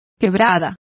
Complete with pronunciation of the translation of ravine.